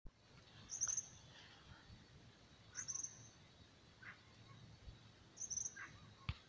Tropical Kingbird (Tyrannus melancholicus)
Life Stage: Adult
Detailed location: El Ceibal
Condition: Wild
Certainty: Photographed, Recorded vocal